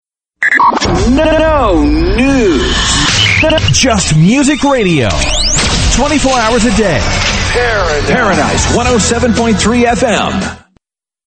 TOP 40